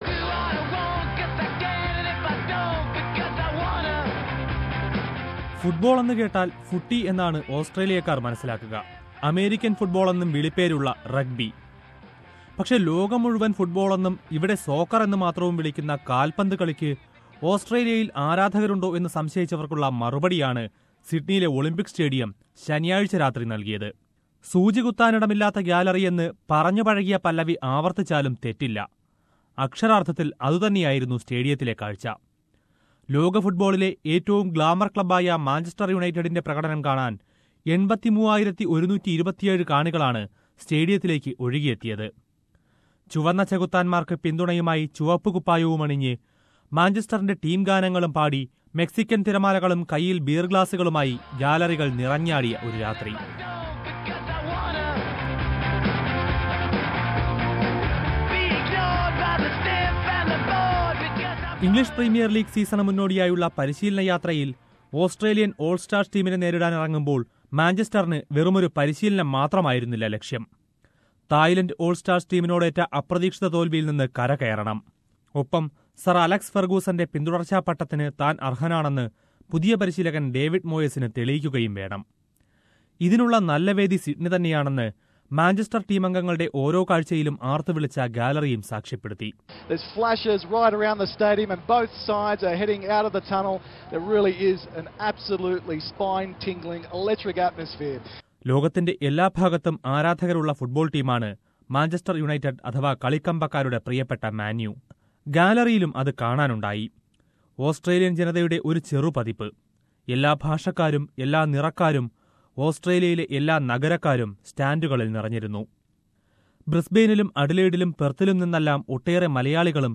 Lets listen to the Malayalees who were there in the galleries...